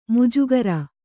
♪ mujugara